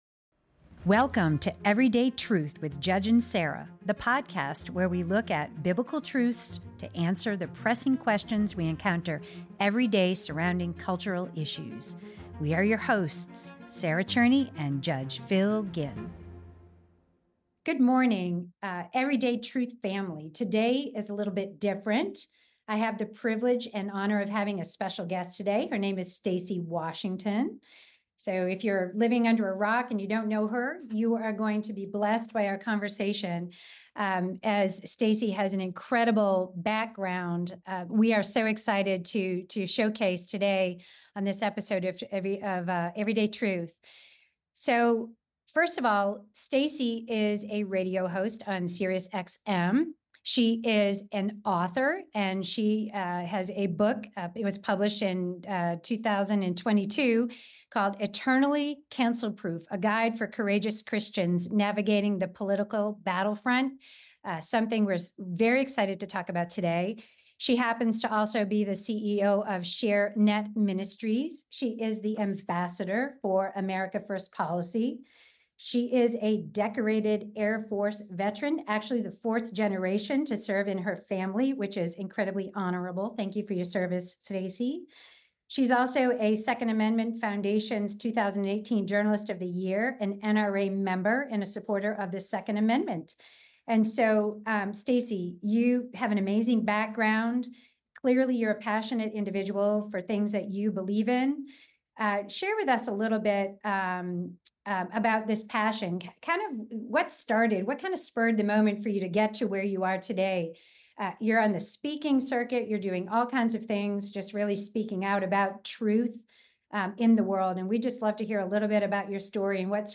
Episode 4: Interview